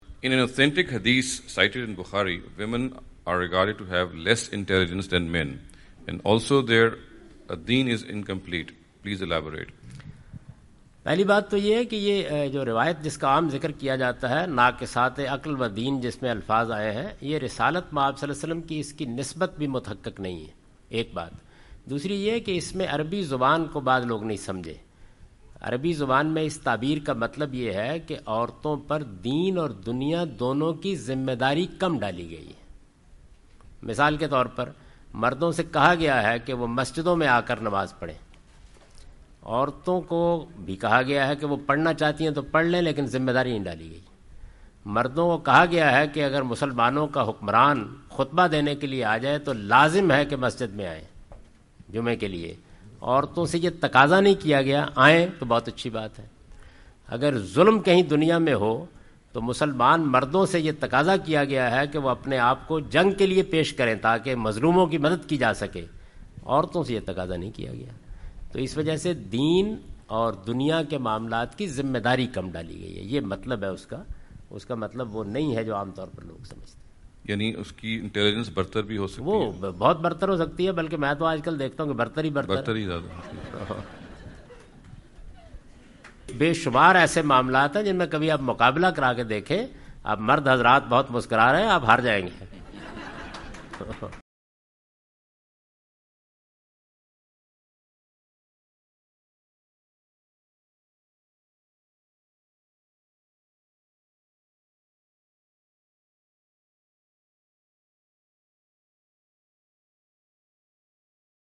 Javed Ahmad Ghamidi answer the question about "Are Women Less Intelligent than Men?" during his US visit.
جاوید احمد غامدی اپنے دورہ امریکہ کے دوران ڈیلس۔ ٹیکساس میں "کیا خواتین مردوں کی نسبت کم ذہین ہوتی ہیں؟" سے متعلق ایک سوال کا جواب دے رہے ہیں۔